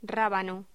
Locución: Rábano
voz
Sonidos: Voz humana